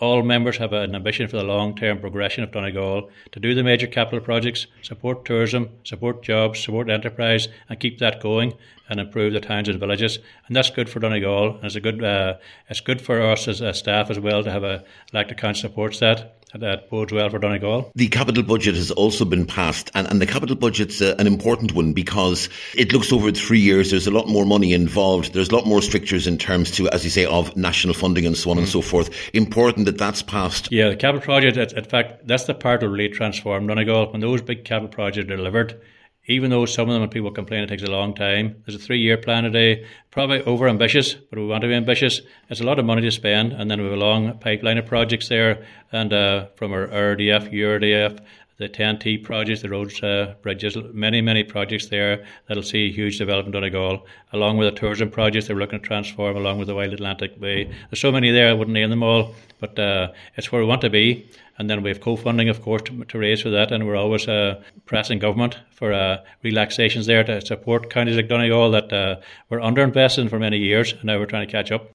Chief Executive John McLaughlin says it’s a very ambitious document, but he and his colleagues will be pressing government to address what’s been a traditional pattern of underinvestment in the county…………..